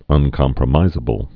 (ŭn-kŏmprə-mīzə-bəl)